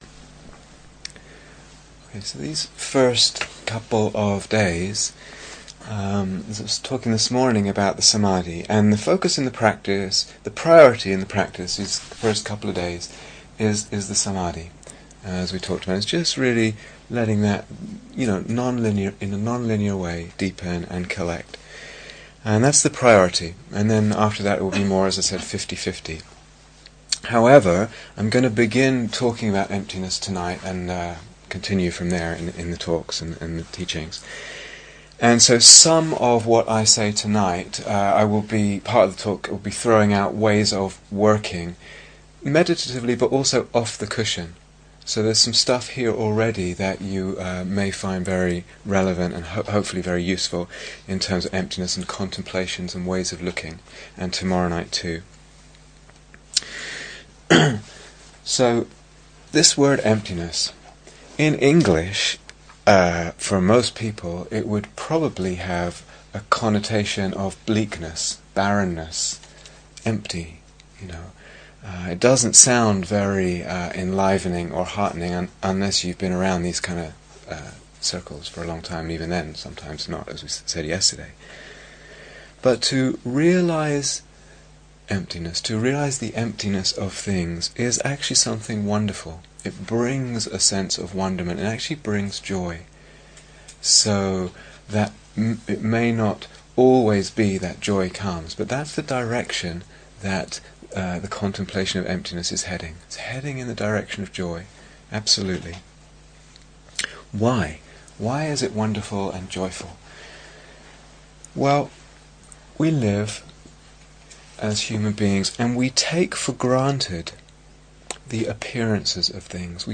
Here is the full retreat on Dharma Seed Please note that these talks are from a 4 week retreat for experienced meditators. The talks and meditations can be listened to in any order or individually, but as they progressively unfold different levels of understanding of Emptiness, they will probably be more fully understood and the practices more easily developed if taken in series.